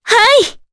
Veronica-Vox_Happy4_jp.wav